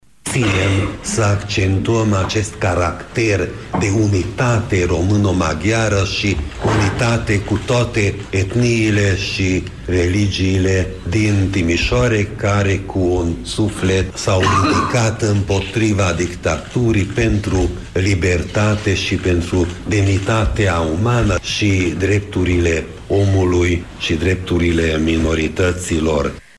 La Biserica Reformată din Piaţa Maria, locul în care s-a declanşat scânteia Revoluţiei, au fost depuse coroane de flori şi s-au aprins lumânări.
Acţiunea, organizată de Consiliul Naţional al Maghiarilor din Transilvania, a avut loc în prezenţa preşedintelui organizaţiei, fostul pastor reformat László Tőkés.